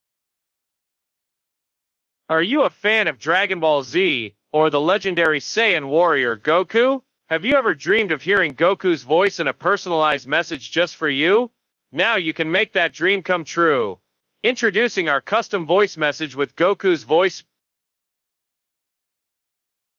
Custom Voice Message with Goku Voice
Goku’s voice, filled with excitement, passion, and a bit of his legendary carefree attitude, will add that special touch to any occasion.
1. Authentic Goku Voice
We work with professional voice actors who can recreate Goku’s voice to perfection.
You’ll get an authentic and energetic performance that brings Goku to life.
• Quality You Can Trust: We use high-quality recording equipment and professional editing to ensure that the final result sounds polished and crisp.